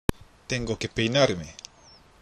（テンゴケ　ペイナールメ）